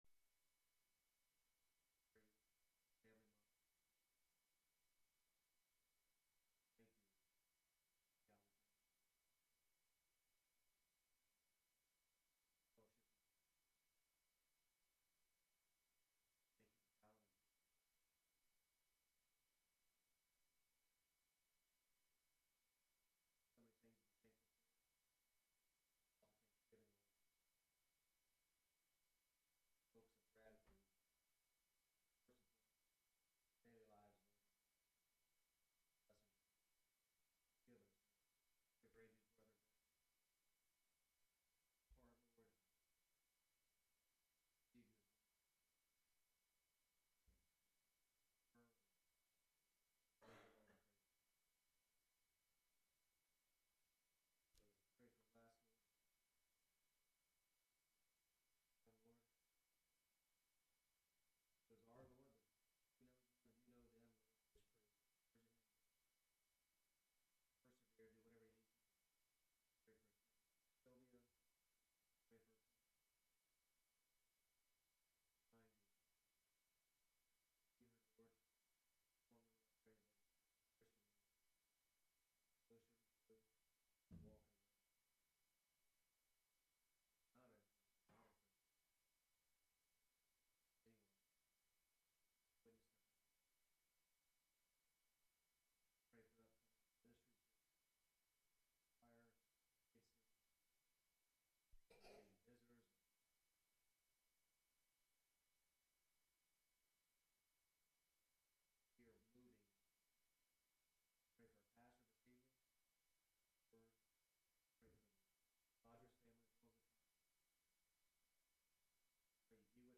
on Sunday morning